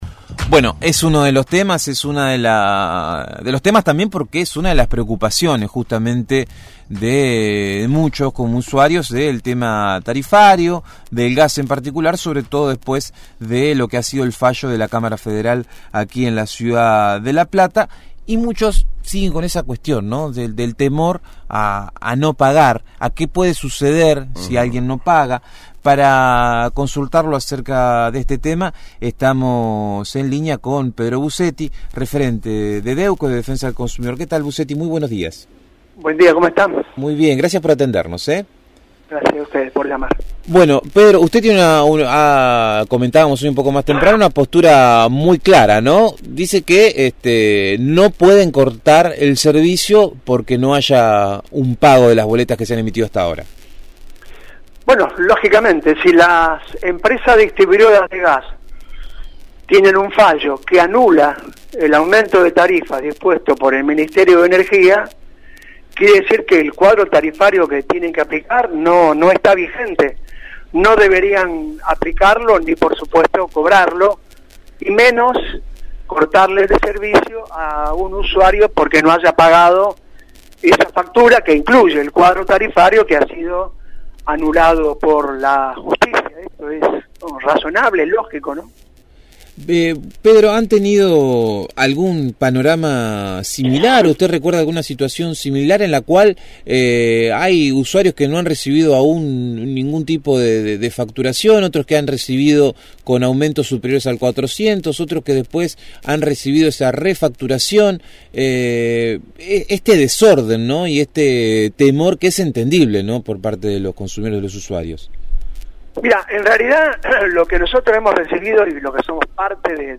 dialogó con el equipo de «El hormiguero» sobre la decisión de pagar o no las facturas de luz y gas alcanzadas por el «tarifazo», y sus consecuencias.